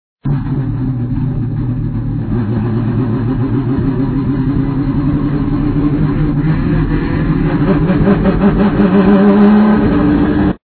Tonos FX Carburando
Salida de Boxes - Chevrolet del TC